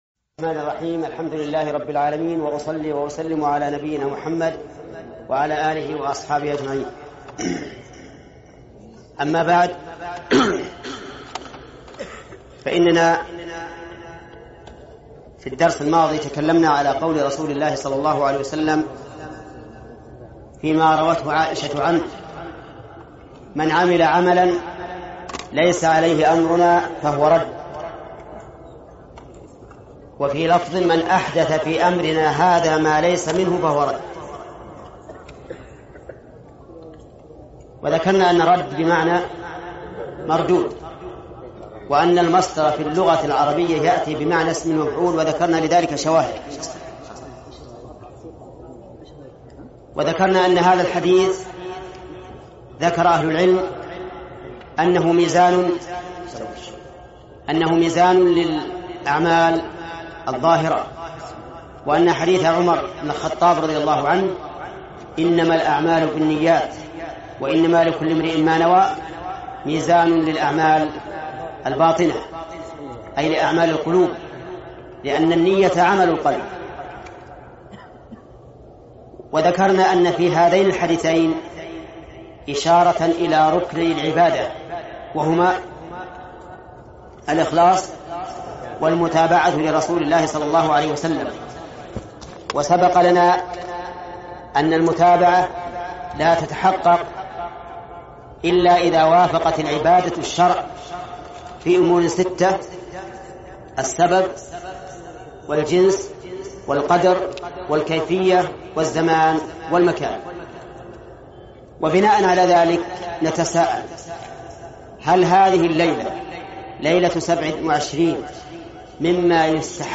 ارسل فتوى عبر "الواتساب" ملف الحج الفتاوى فتاوى ودروس في المسجد الحرام - العمرة - الشيخ محمد بن صالح العثيمين المادة هل للعمرة فضل في ليلة القدر؟!!!